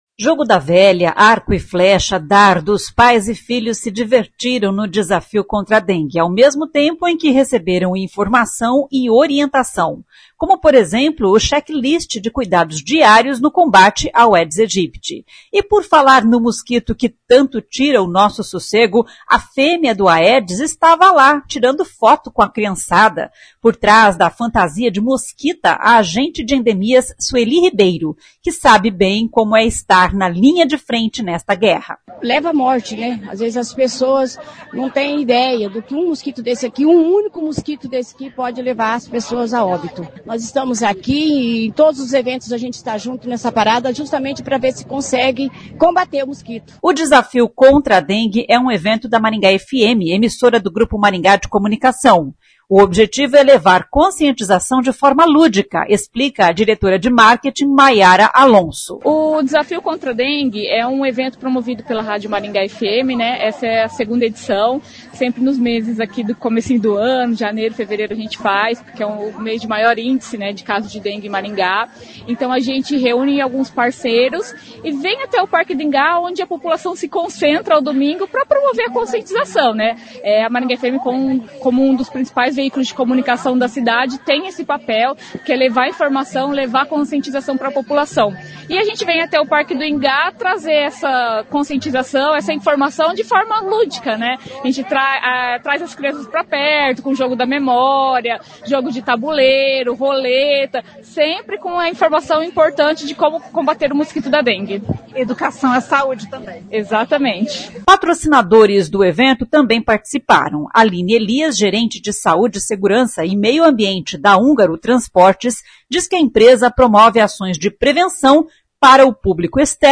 A Maringá FM, emissora do Grupo Maringá de Comunicação (GMC) promoveu neste domingo (23) mais uma edição do “Desafio contra a dengue”.